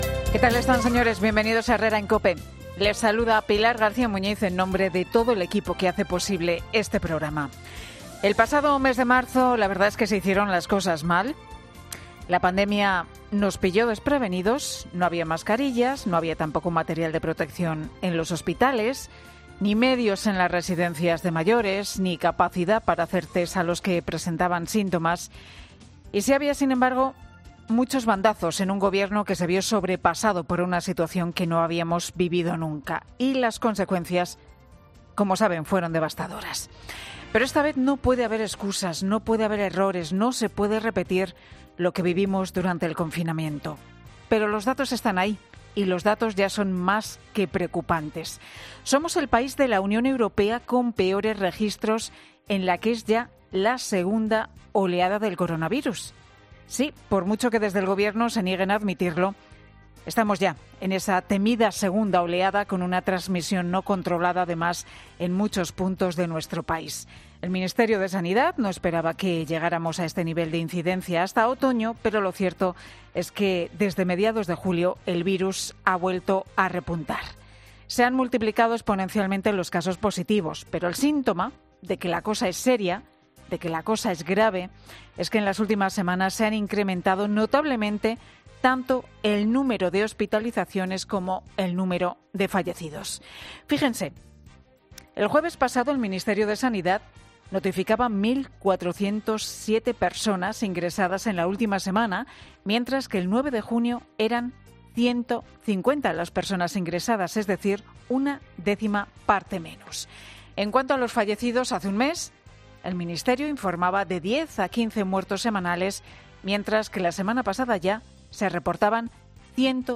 AUDIO: La disparidad de decisiones judiciales ante las medidas adoptadas por el gobierno central y las CC.AA, entre los asuntos del monólogo de Pilar...